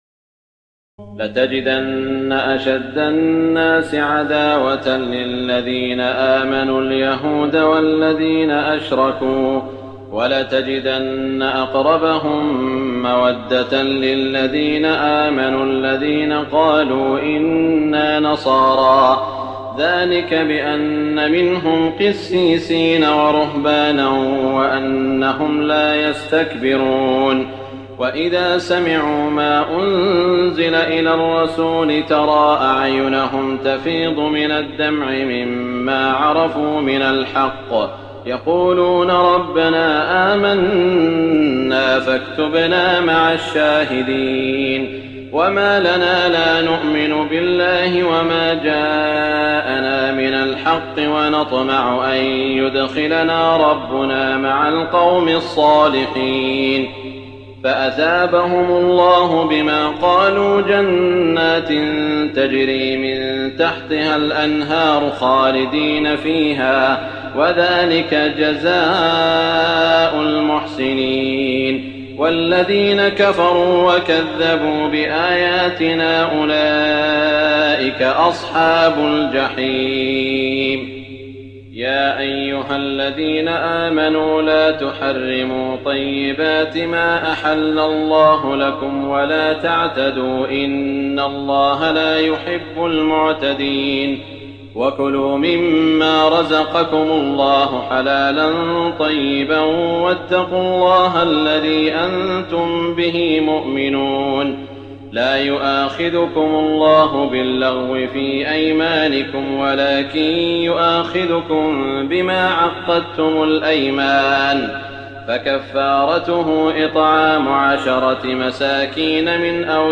تراويح الليلة السادسة رمضان 1419هـ من سورتي المائدة (82-120) و الأنعام (1-36) Taraweeh 6 st night Ramadan 1419H from Surah AlMa'idah and Al-An’aam > تراويح الحرم المكي عام 1419 🕋 > التراويح - تلاوات الحرمين